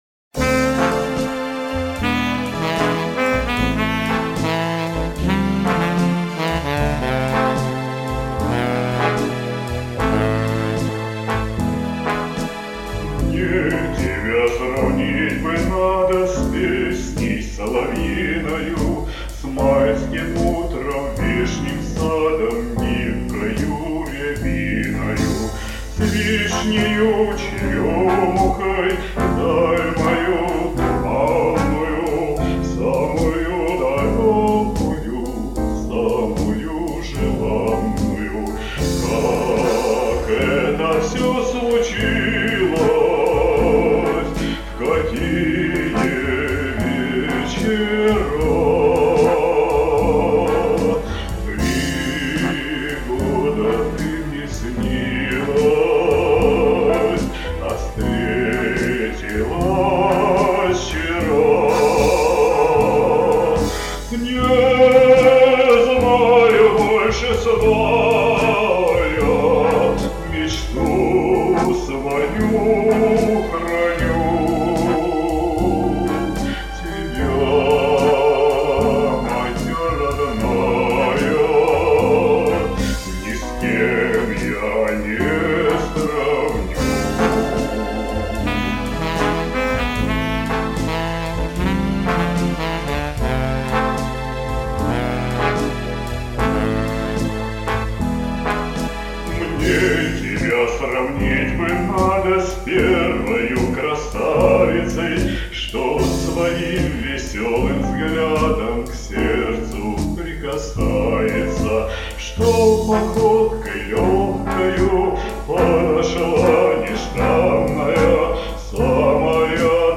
выбрал блюзовый вариант
вы включили свои фишечки с акцентом на вибрато в конце слов.
Тёплое и светлое.